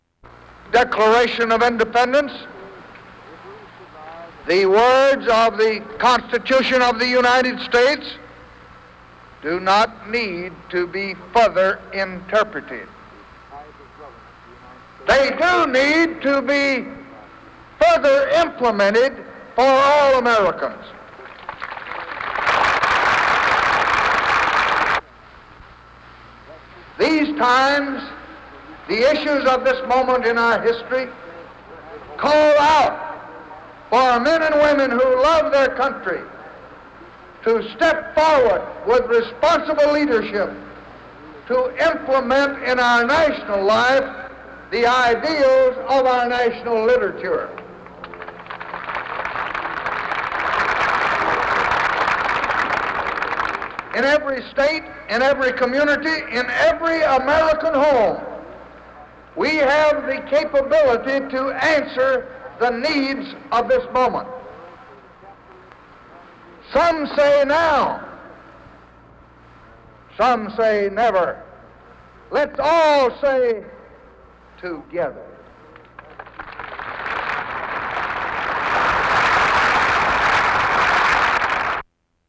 Excerpt of remarks by Lyndon B. Johnson at Gettysburg, Pennsylvania on July 4, 1963